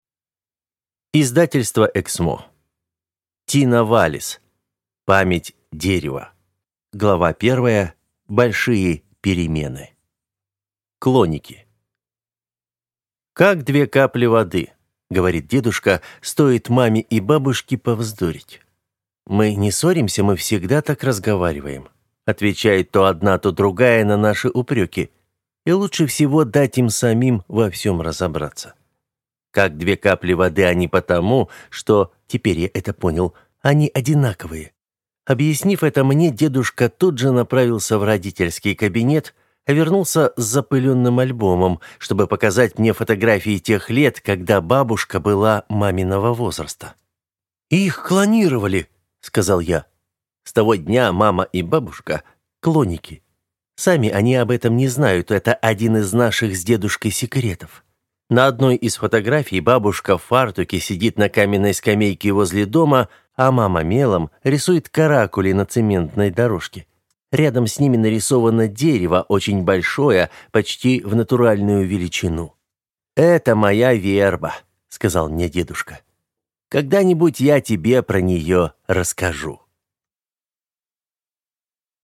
Аудиокнига Память дерева | Библиотека аудиокниг